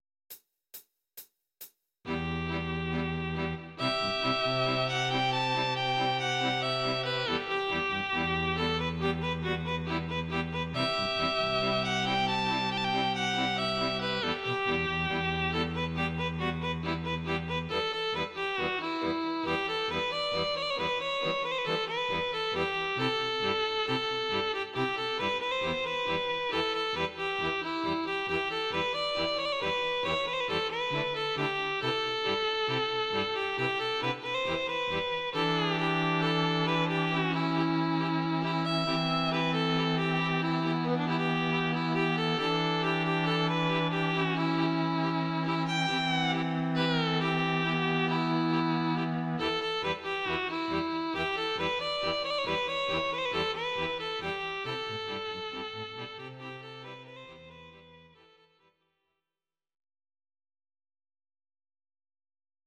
These are MP3 versions of our MIDI file catalogue.
Please note: no vocals and no karaoke included.
Violin solo